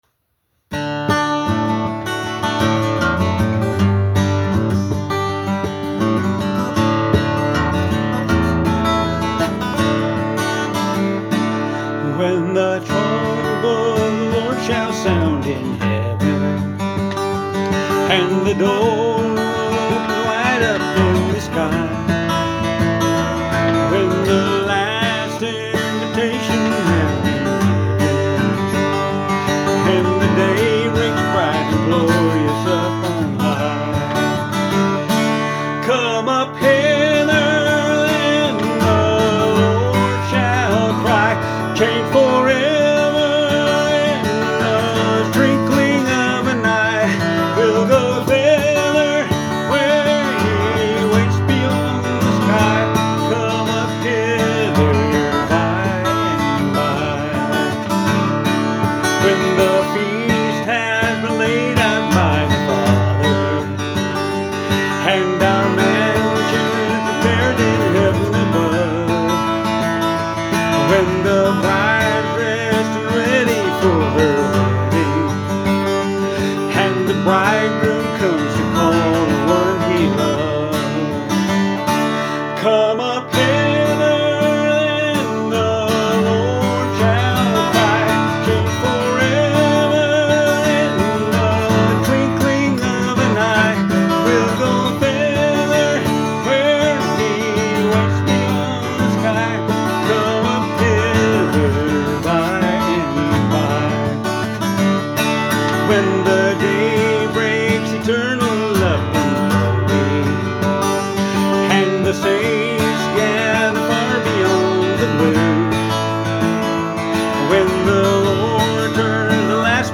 Christian Country